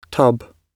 tub-gb.mp3